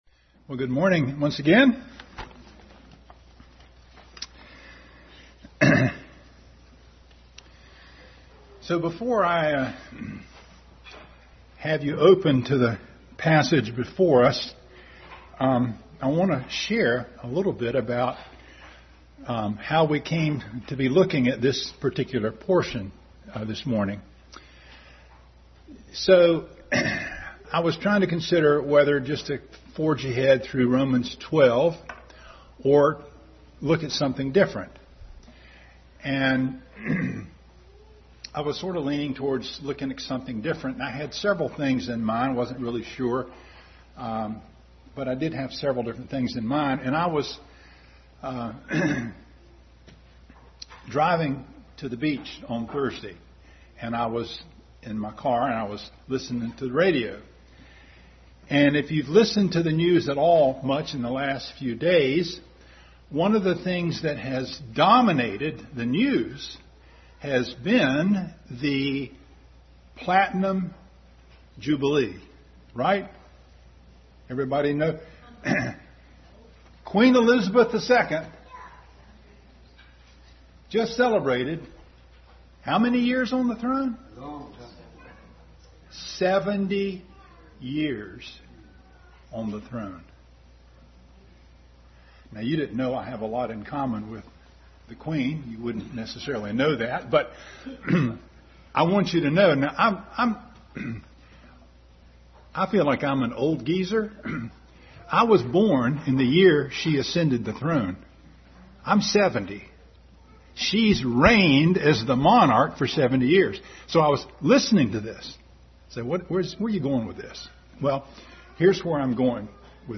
2 Samuel 7:1-29 Passage: 2 Samuel 7:1-29, 1 Chronicles 28:3, 22:7-8, Mark 8:31, 1 Corinthians 15:25, Psalm 8:4, 27:4 Service Type: Family Bible Hour Family Bible Hour Message.